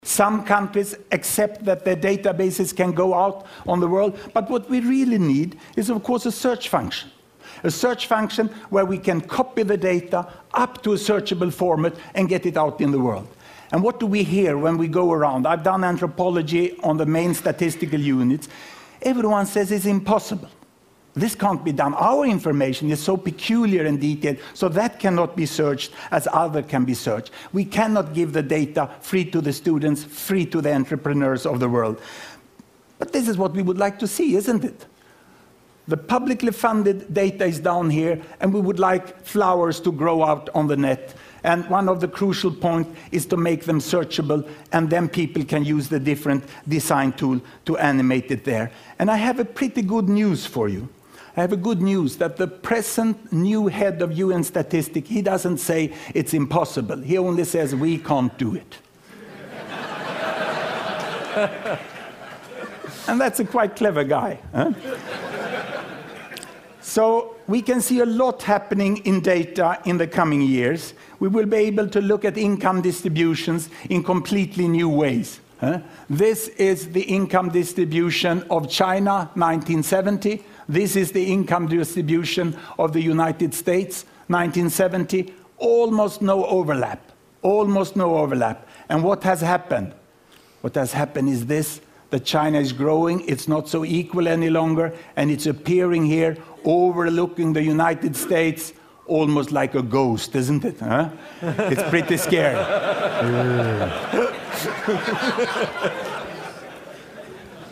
TED演讲：用前所未有的好方法诠释数字统计(10) 听力文件下载—在线英语听力室